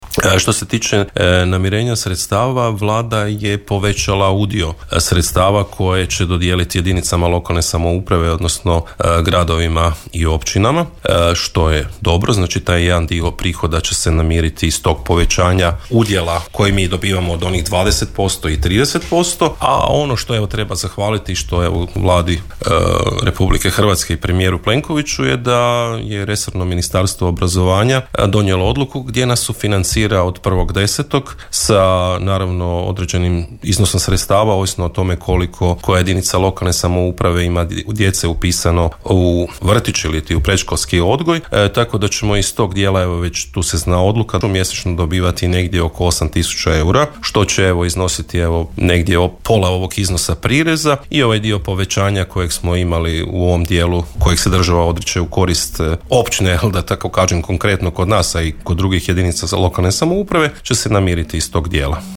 „Općina Lekenik neće podizati stope poreza nego ćemo ostati na sadašnjim stopama od 20 i 30 posto”, ističe načelnik Perović te dodaje